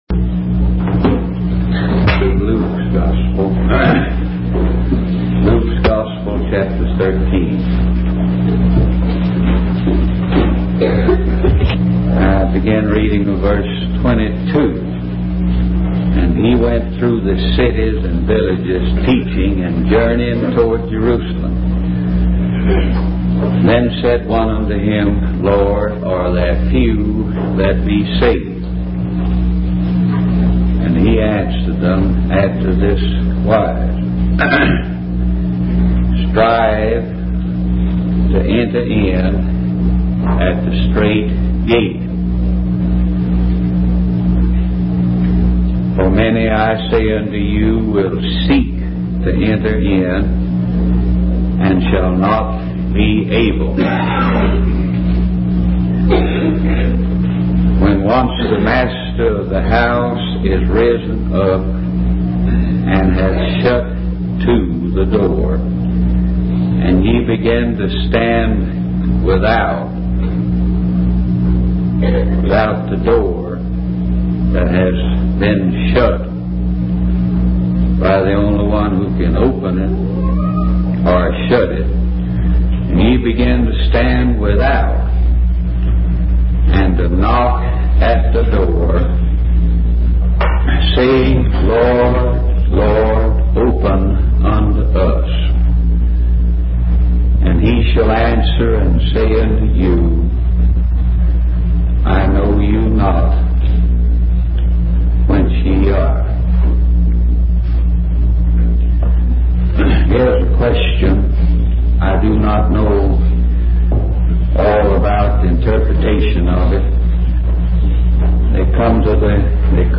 In this sermon, the speaker emphasizes the lack of vision among God's people when it comes to the need for salvation. He highlights the difficulty of living in a world where everyone is headed towards destruction.